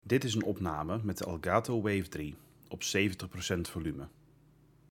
De volumetesten zijn gedaan met een afstand naar de microfoon van 10 cm en zonder verbeteringen ingeschakeld in Open Broadcaster Software.
Elgato Wave:3 - 70% volume
Microfoonopname-Elgato-Wave3-70-volume.mp3